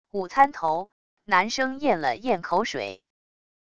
午餐头……男生咽了咽口水wav音频